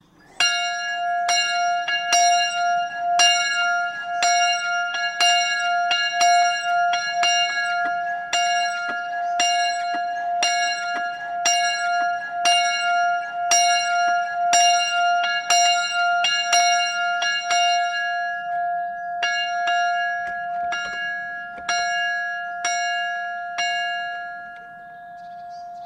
Klokje van de Kluis, a.u.b. clicken op afbeelding (tekening klokje):
klokken_kluis.mp3